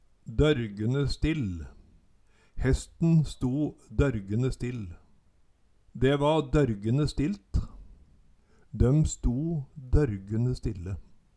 Høyr på uttala Ordklasse: Uttrykk Kategori: Uttrykk Attende til søk